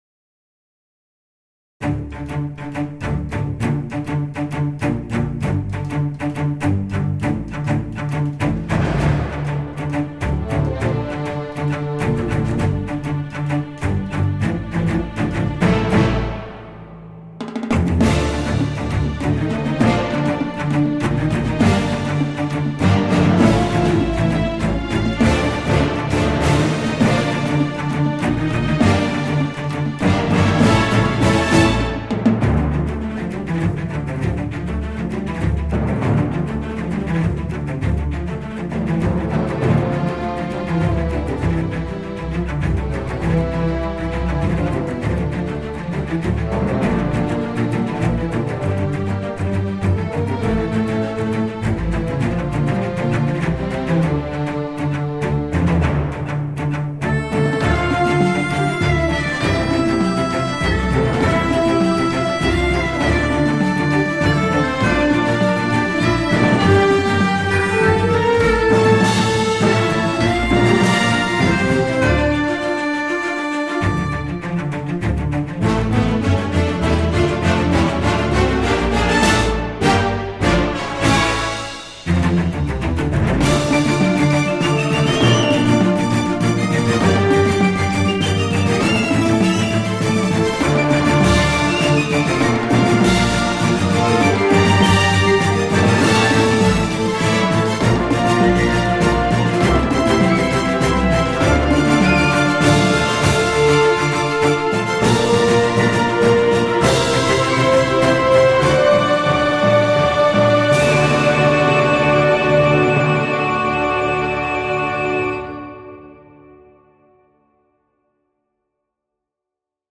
Звуки пиратских приключений